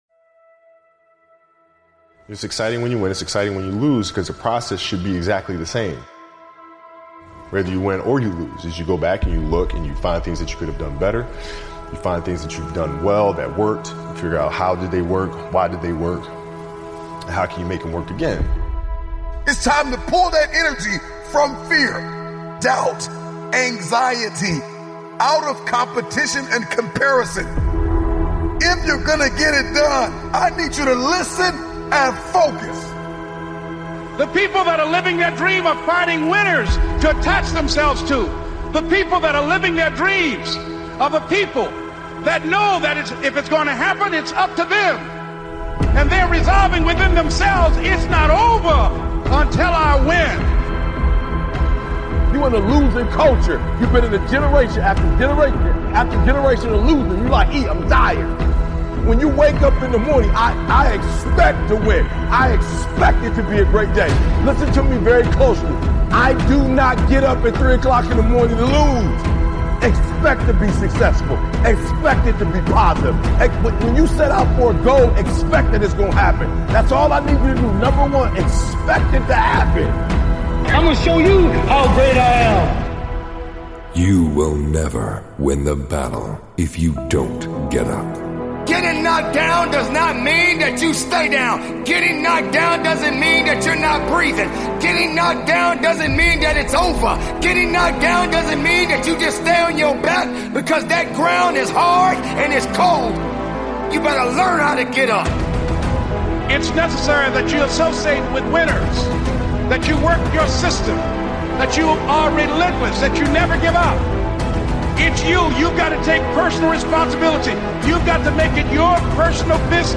Speakers: Denzel Washington